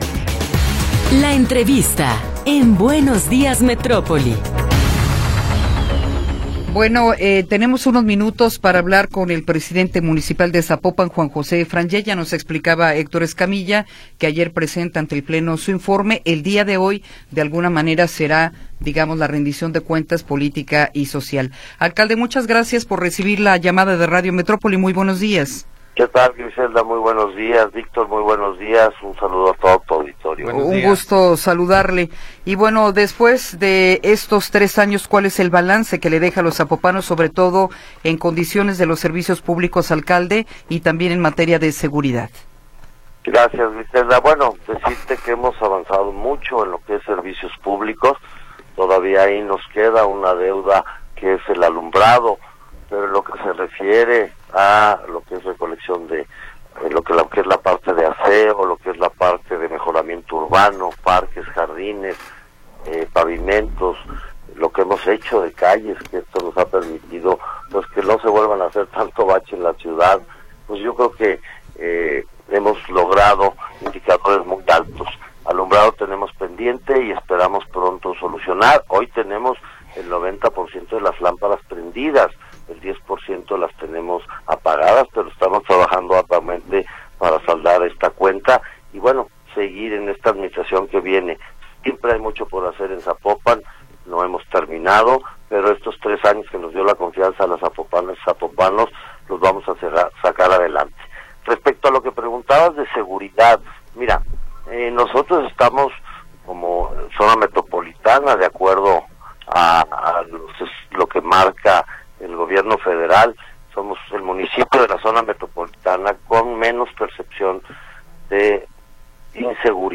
Entrevista con Juan José Frangie